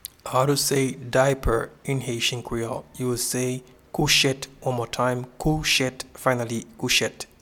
Pronunciation and Transcript:
Diaper-in-Haitian-Creole-Kouchet.mp3